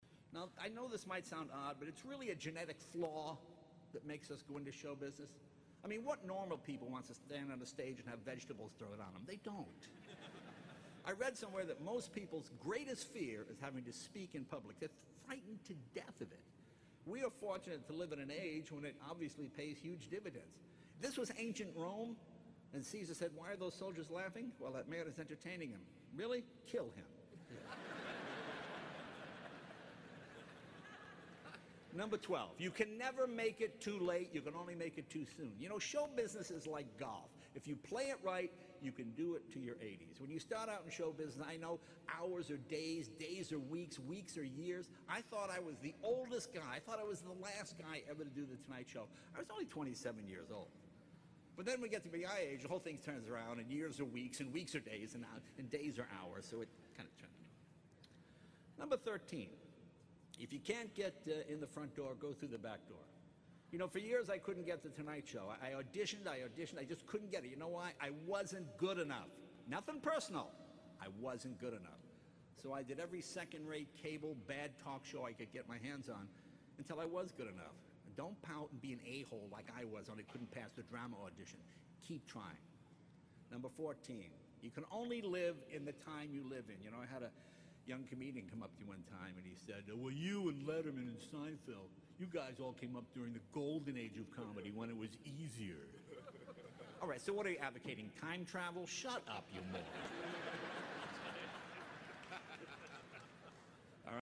在线英语听力室公众人物毕业演讲 第212期:杰雷诺2014爱默生学院(10)的听力文件下载,《公众人物毕业演讲》精选中西方公众人物的英语演讲视频音频，奥巴马、克林顿、金庸、推特CEO等公众人物现身毕业演讲专区,与你畅谈人生。